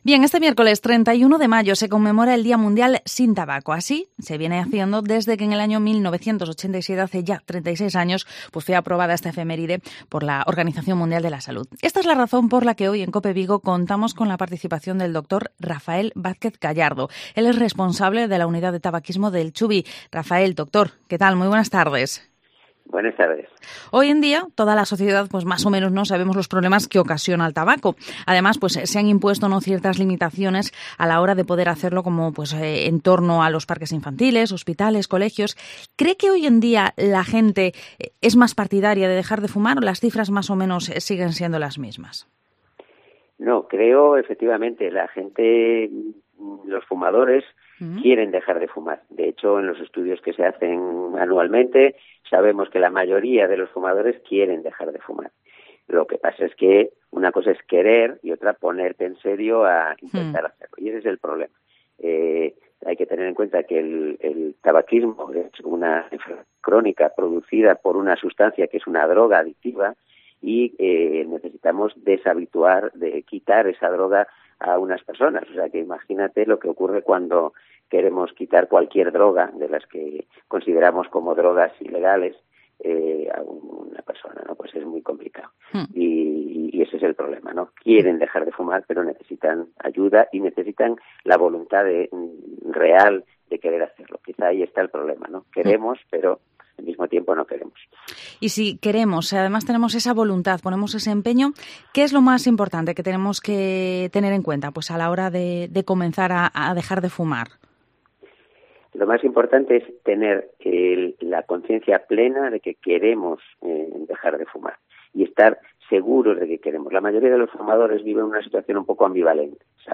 Vigo Entrevista Día Mundial Sin Tabaco.